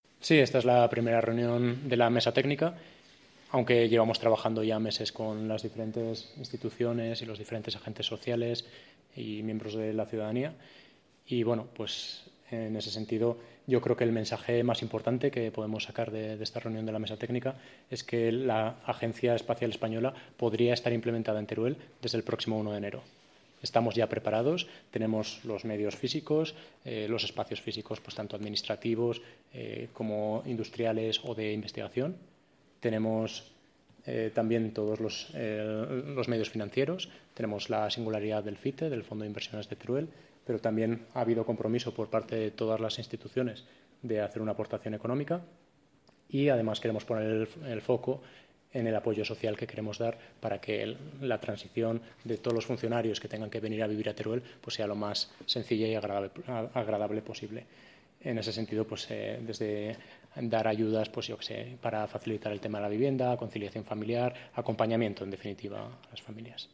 «Garantizamos espacios, financiación y medidas de acompañamiento a los funcionarios» El director general Ramón Guirado valora la reunión de la comisión técnica